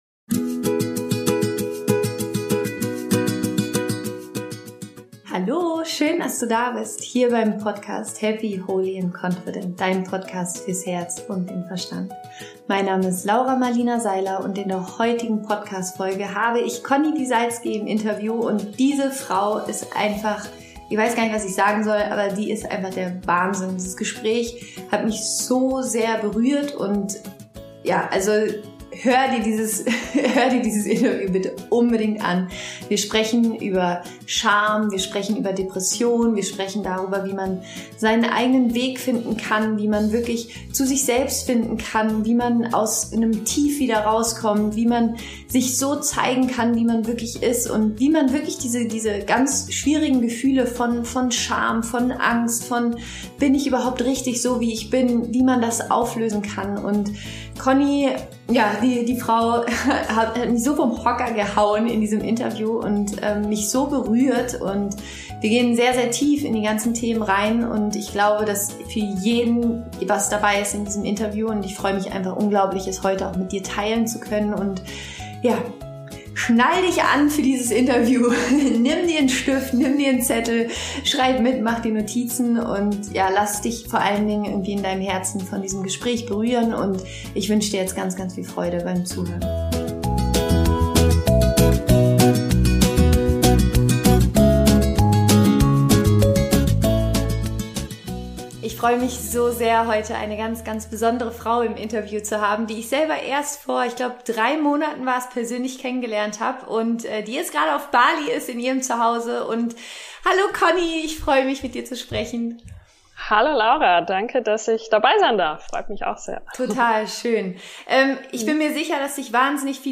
Wir nehmen dich mit in ein Gespräch über innere Wahrheit, Selbstliebe, Heilung und Freiheit.